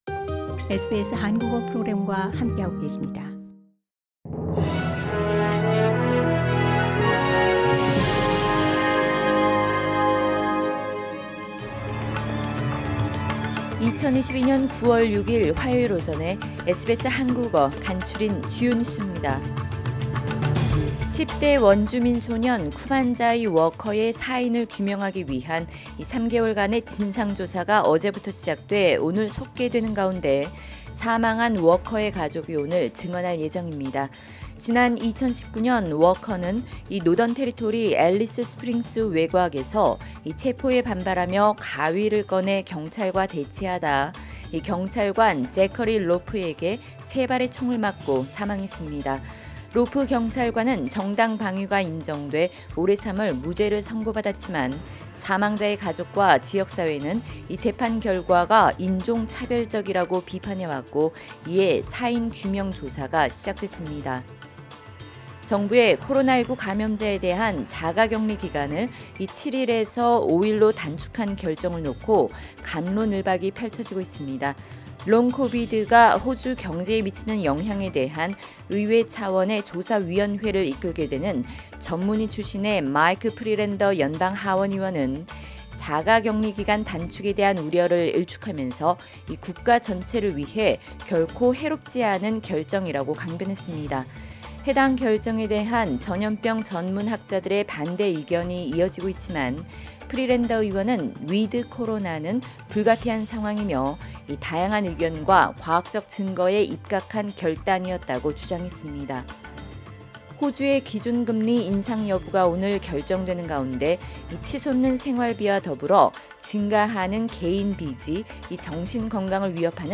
SBS 한국어 아침 뉴스: 2022년 9월 6일 화요일
2022년 9월 6일 화요일 아침 SBS 한국어 간추린 주요 뉴스입니다.